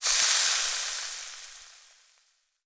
cooking_sizzle_burn_fry_01.wav